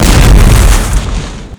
sol_reklam_link sag_reklam_link Warrock Oyun Dosyalar� Ana Sayfa > Sound > Weapons > Grenade Dosya Ad� Boyutu Son D�zenleme ..
WR_blowup.wav